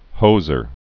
(hōzər)